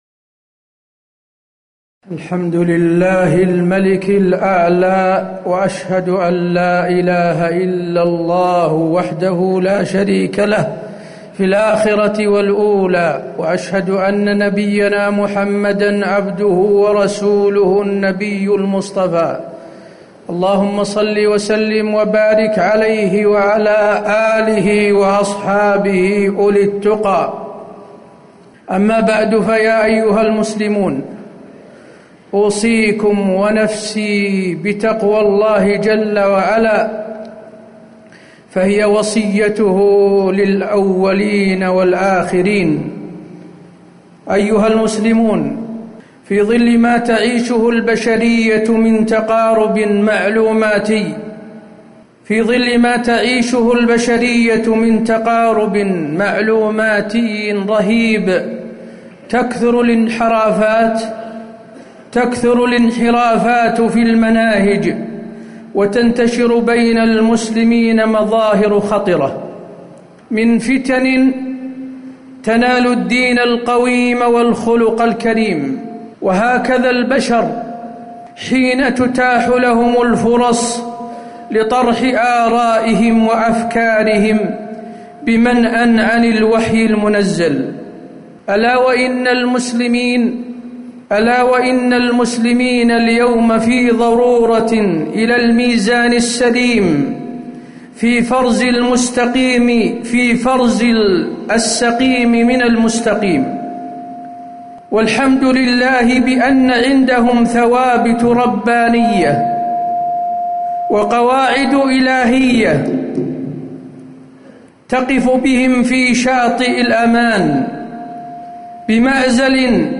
تاريخ النشر ٢ شعبان ١٤٣٨ هـ المكان: المسجد النبوي الشيخ: فضيلة الشيخ د. حسين بن عبدالعزيز آل الشيخ فضيلة الشيخ د. حسين بن عبدالعزيز آل الشيخ الاستجابة لأمر الله